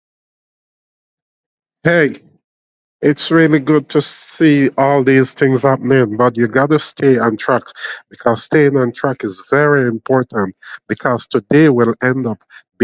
Voix IA magistrale de prise de parole en public
Synthèse vocale
Précision oratoire
Cadence dynamique